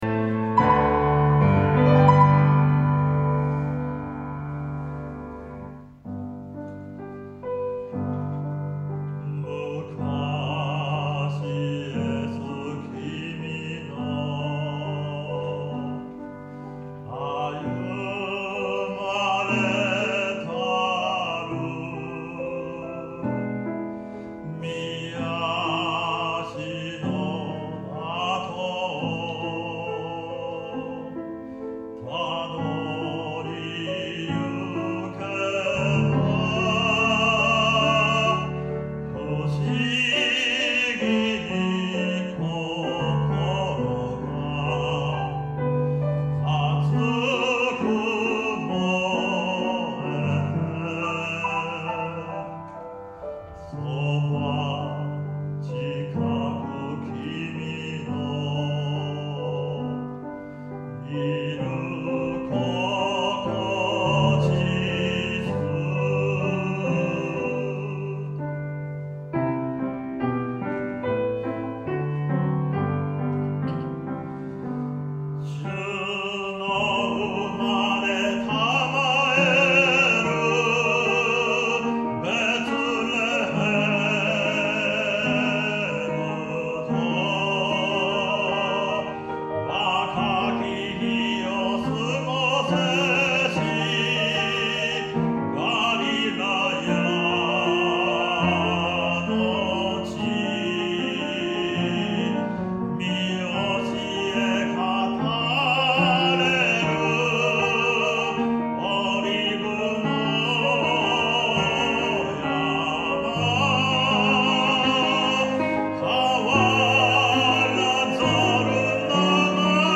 第37回学位授与式　特別賛美
2025年3月1日（土）午後2時 於牛込キリスト教会
バリトン
ピアノ